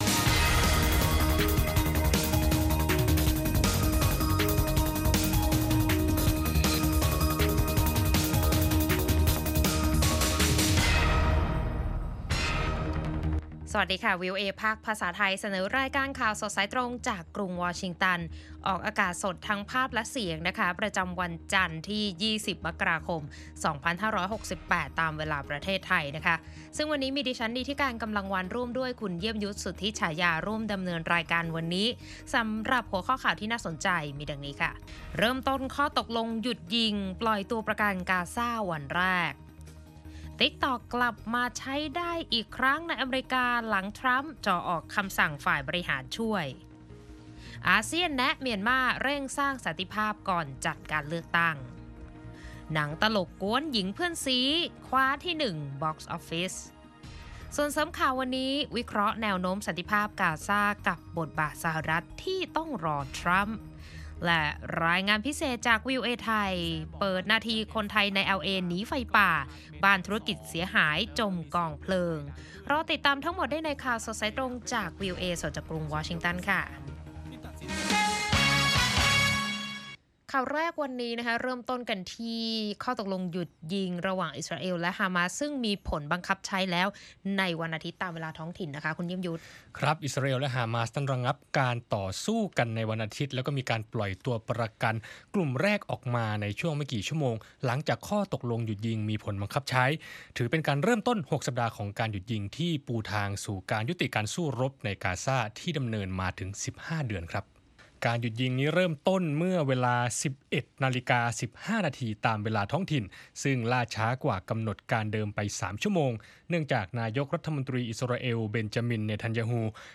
ข่าวประจำวัน